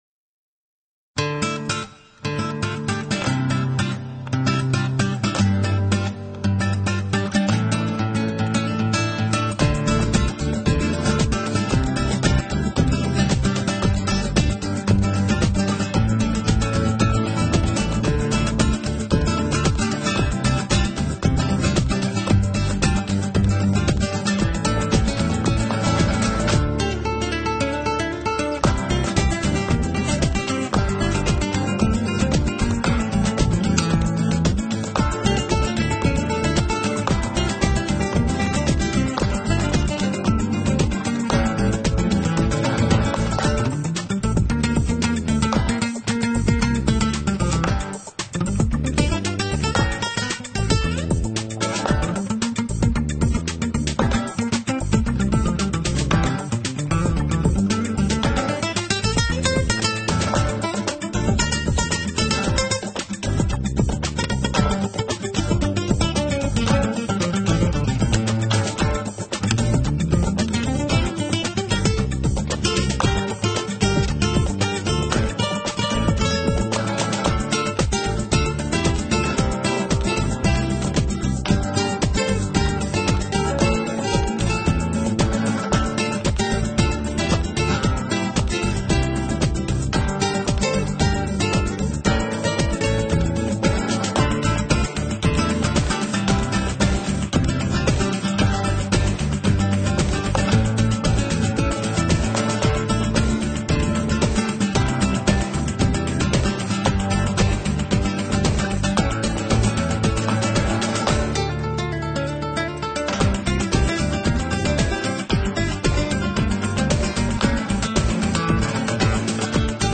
音乐类型：flamenco 弗拉明戈
音乐风格：Adult Alternative,Ethnic Fusion,Flamenco,Contemporary
乐句巧妙，颤音充满灵魂，曲目韵律也效果不错。